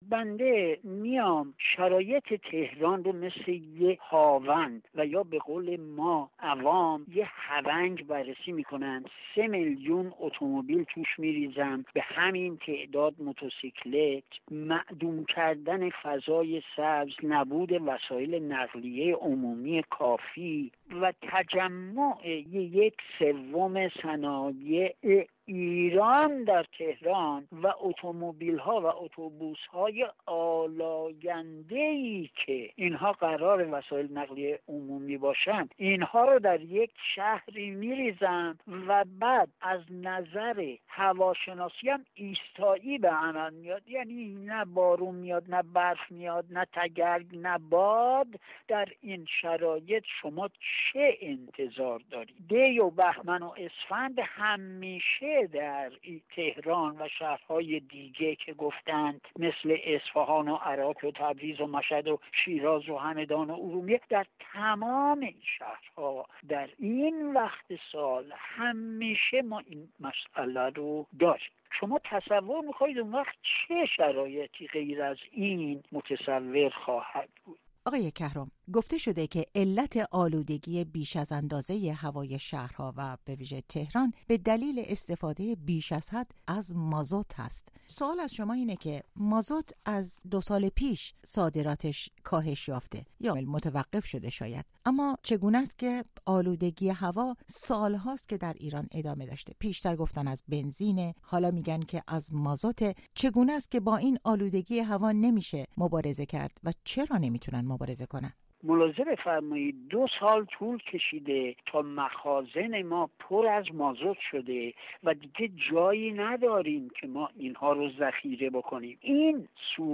برنامه‌های رادیویی